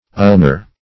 Search Result for " ulnar" : Wordnet 3.0 ADJECTIVE (1) 1. relating to or near the ulna ; The Collaborative International Dictionary of English v.0.48: Ulnar \Ul"nar\, a. (Anat.)